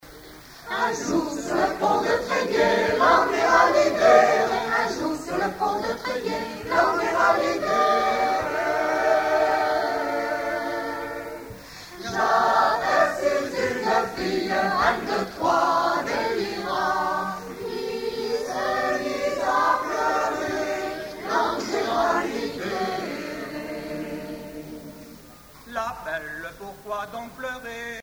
Informateur(s) Mor-Gan Chorale
Chansons de la soirée douarneniste 88
Pièce musicale inédite